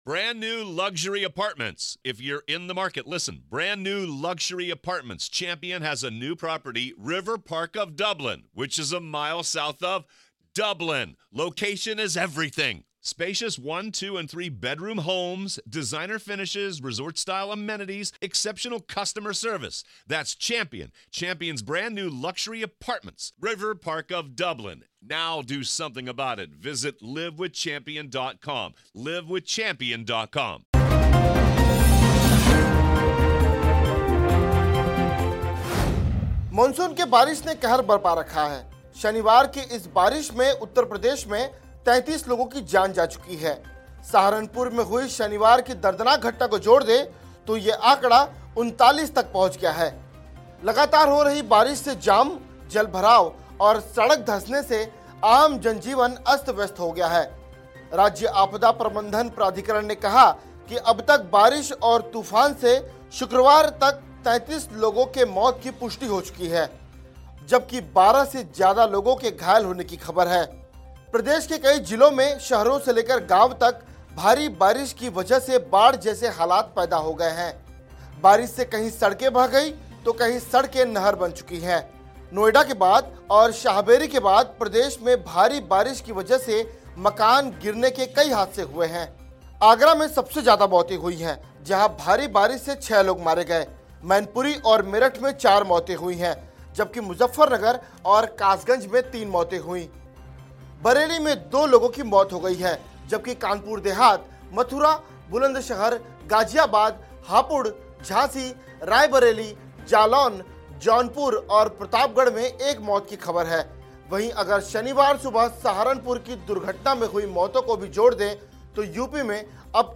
न्यूज़ रिपोर्ट - News Report Hindi / यूपी में मौत का तूफान, भारी बारिश से 48 घंटों में 49 की मौत, 17 राज्यों में अलर्ट जारी